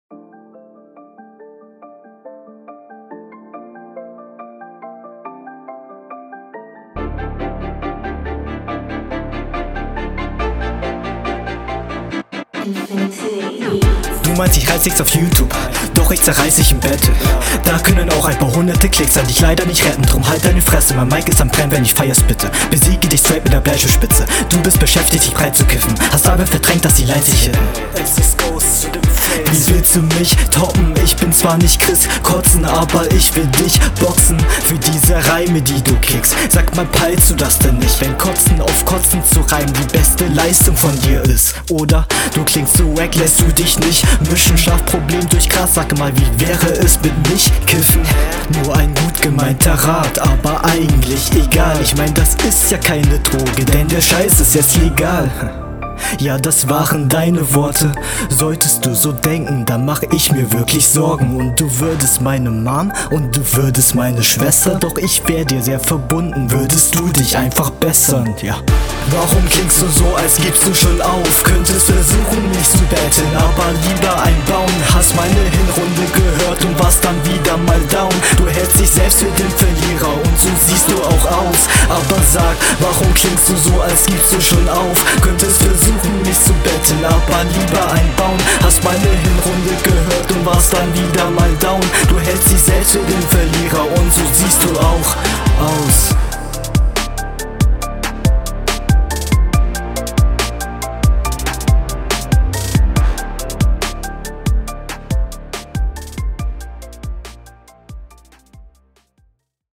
Mit sichererem Stimmeinsatz und besserem Mix wäre das ein Banger.